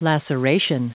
Prononciation du mot laceration en anglais (fichier audio)
Prononciation du mot : laceration